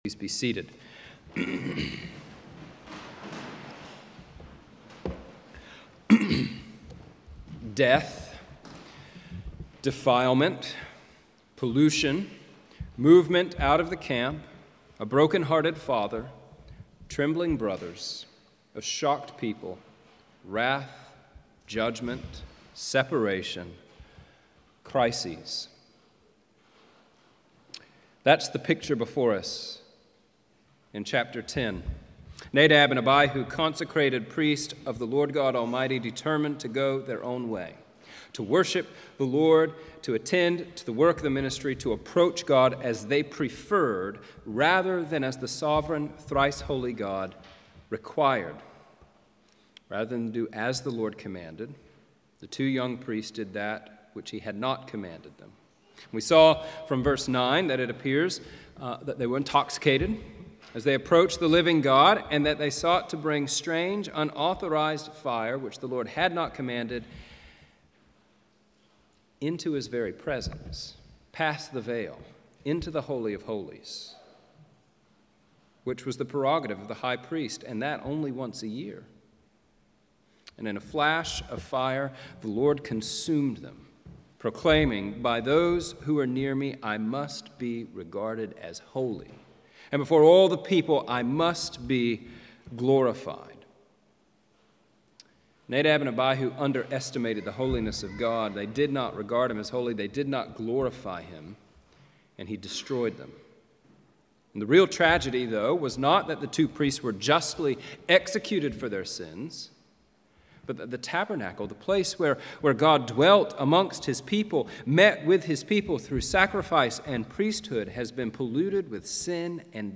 Passage: Hebrews 2, 2 Corinthians 7:8-13, Leviticus 10:8-20 Service Type: Sunday Evening « Who Then Is This?